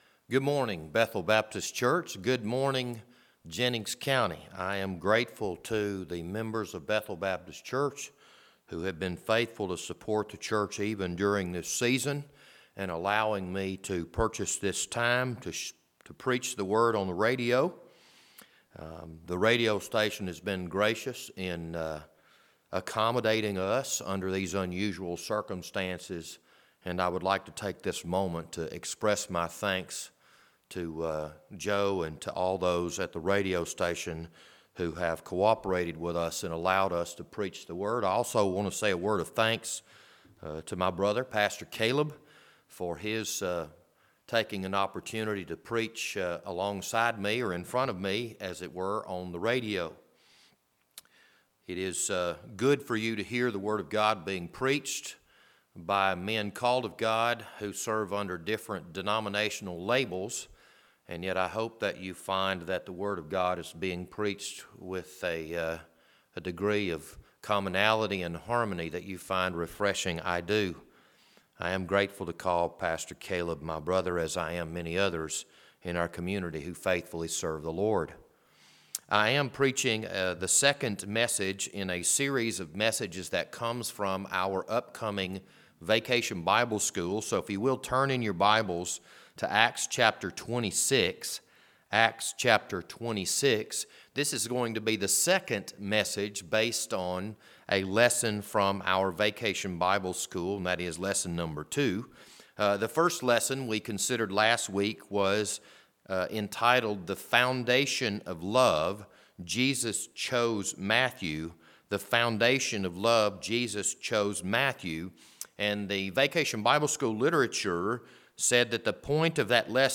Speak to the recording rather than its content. This Sunday morning sermon was recorded for April 26, 2020.